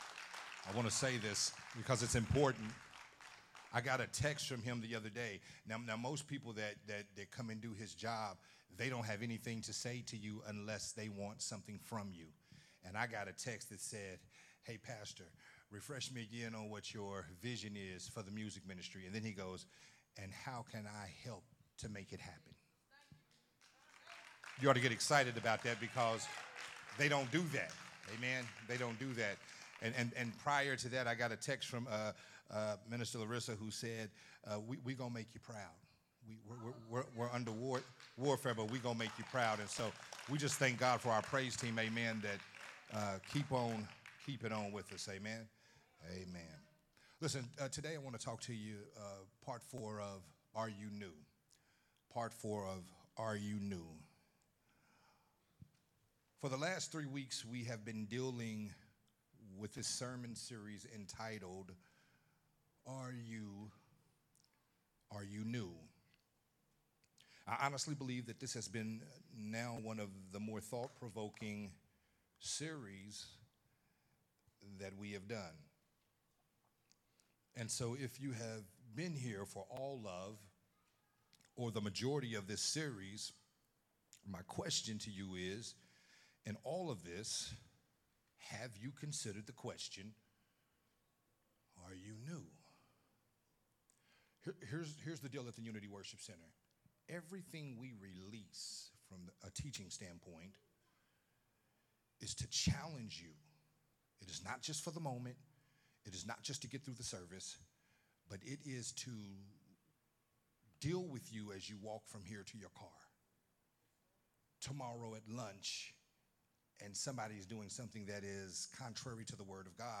recorded at Unity Worship Center on September 10th, 2023.